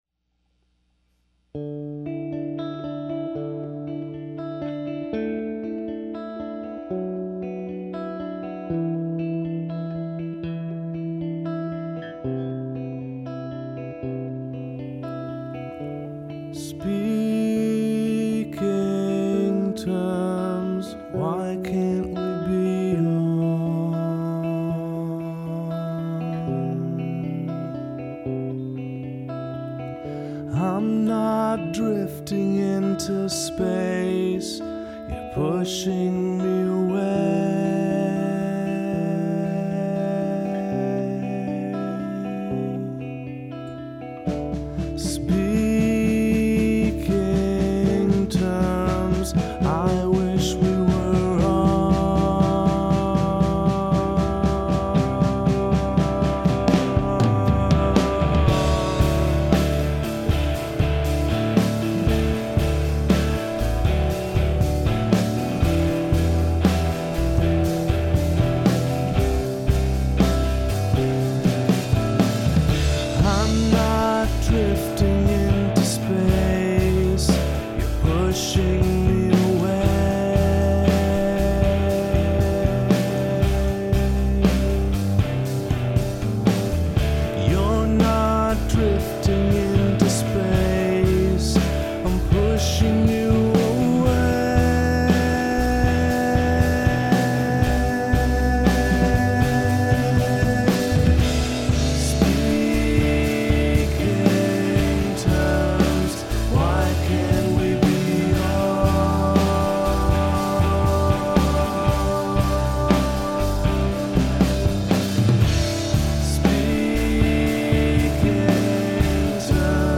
(still need to order and install that new hard drive.) fortunately it was only the vocals that i had to completely rerecord.